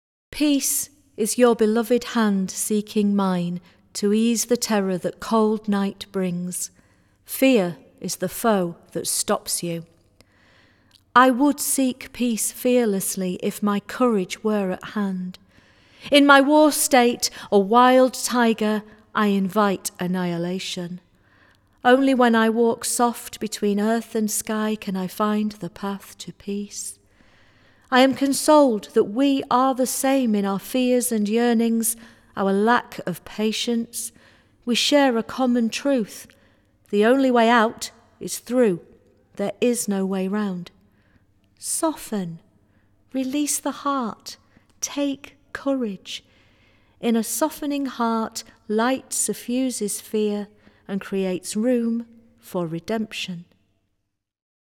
Recorded at Craxton Studios, May 12, 2019
Jazz and poetry commemorating the end of The First World War